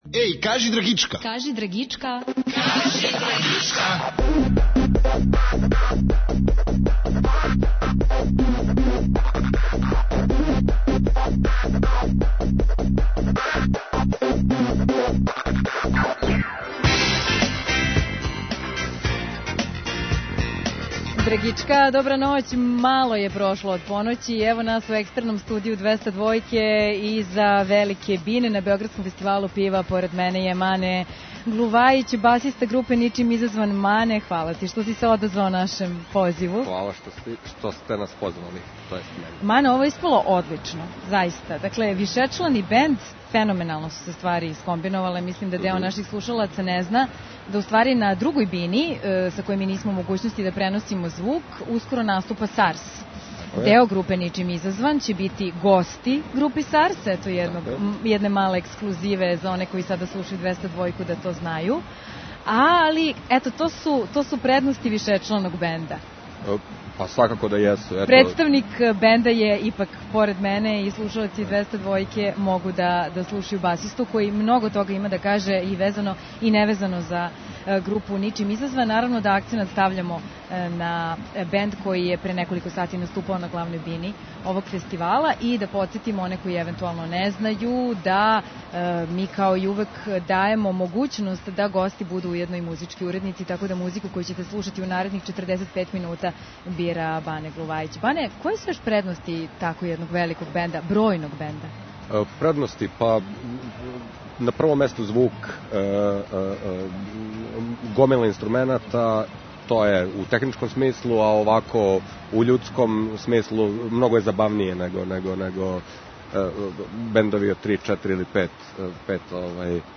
Драгичку ноћас емитујемо из нашег екстерног студија са Бир Феста. Гости су чланови бенда Ничим изазван.